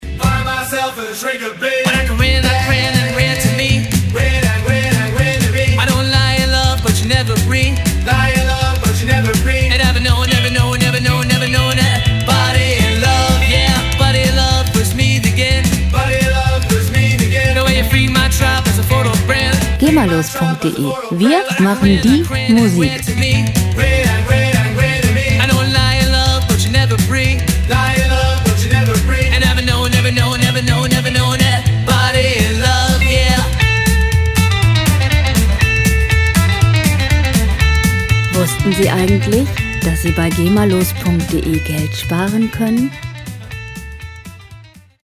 Musikstil: Rockabilly
Tempo: 245 bpm
Tonart: A-Dur
Charakter: rasant, wild